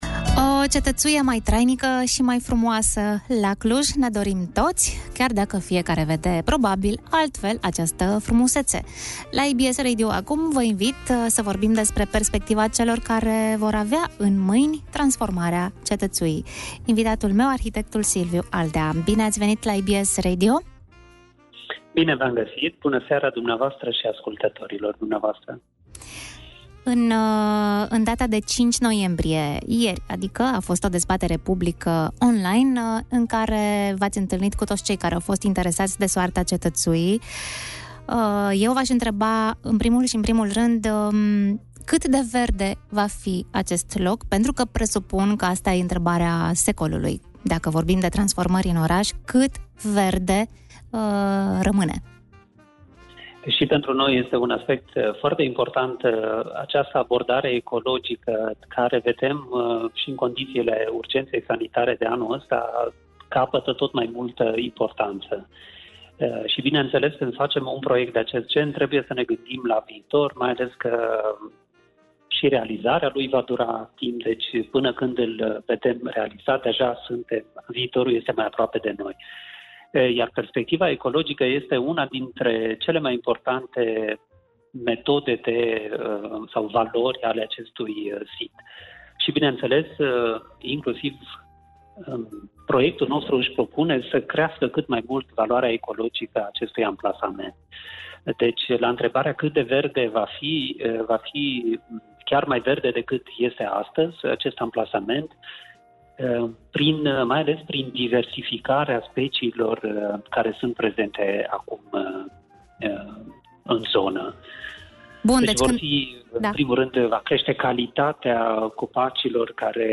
Cetatea în sine, care are o valoare istorică inestimabilă, este unul dintre obiective, dar pe de altă parte proiectul vizează și dealul în sine. Astfel, restaurarea privește și forma de relief care are, la rândul ei, o mare valoare geologică, fiind practic un fost fund de mare, care azi e plin de cochilii și relicve. Mai multe aflați din interviul